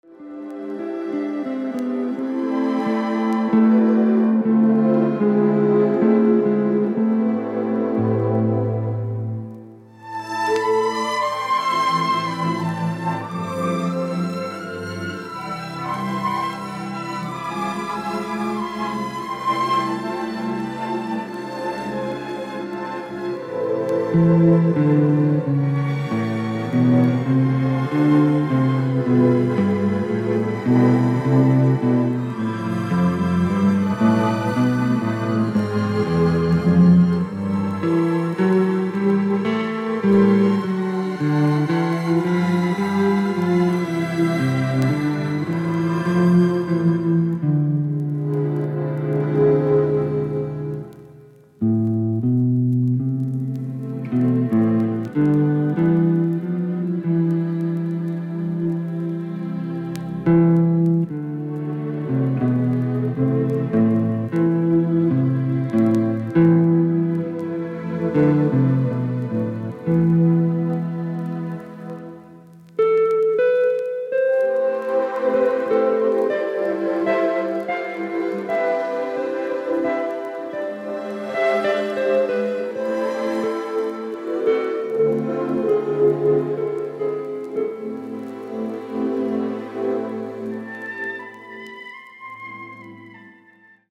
クラシカルなサウンドが今の季節丁度良いですね！！！